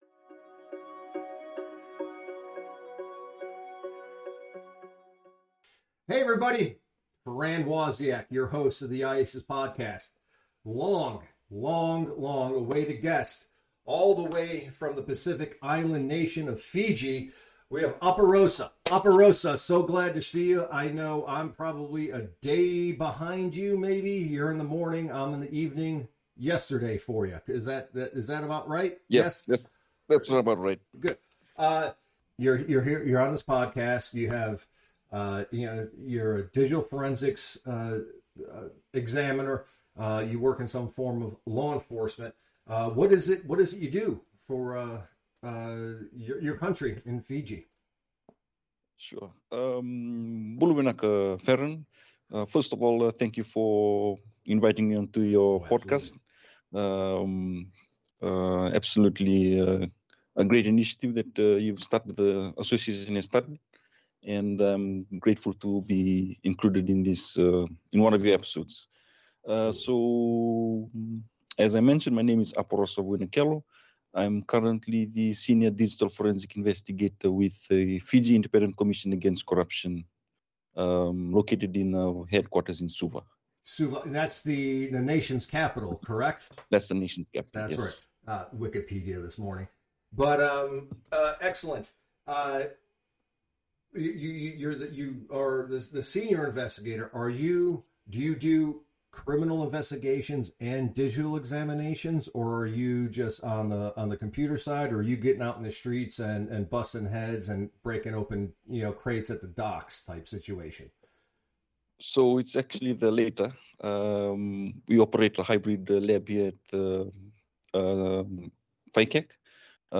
Interview with IACIS Member from Fiji